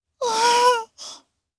Lavril-Vox-Laugh_jp.wav